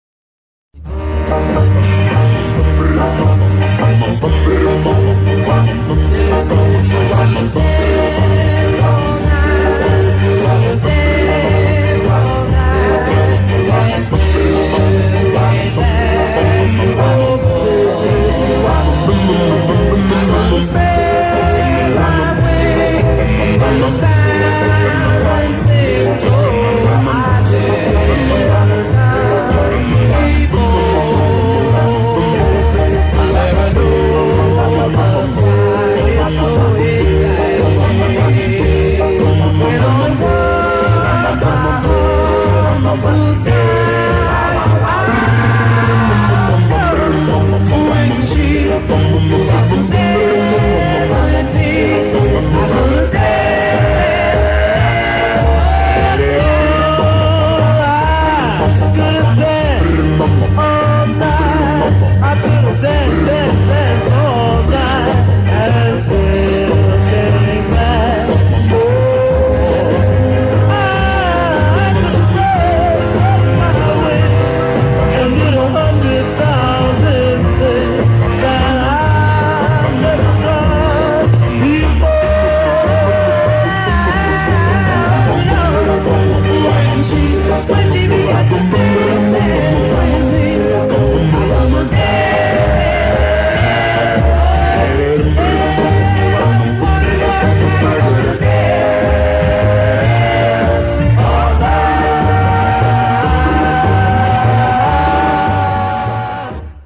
THESE SOUNDS ARE IN REALAUDIO STEREO!